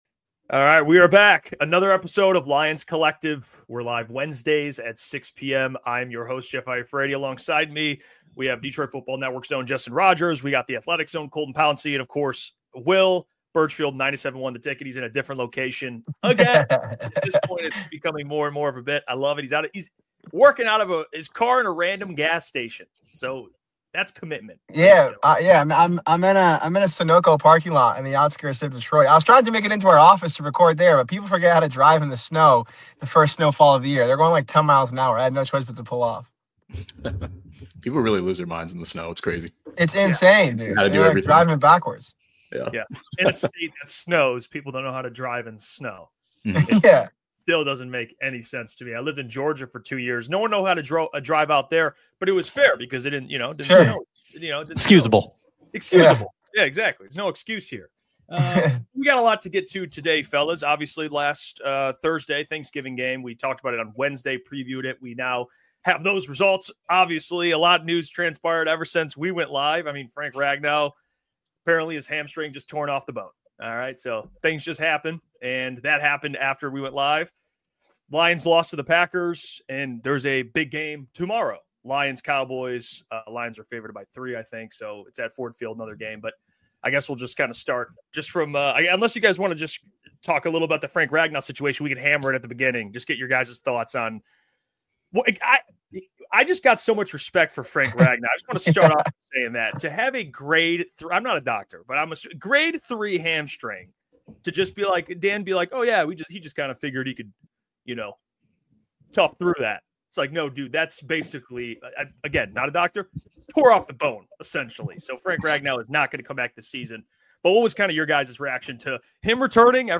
Make sure to subscribe to Lions Collective for new roundtable episodes every week, live, on Wednesday at 6 pm ET You can also hear every episode of Lions Collective wherever you get your podcasts!